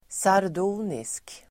Ladda ner uttalet
Uttal: [sar_do:nisk]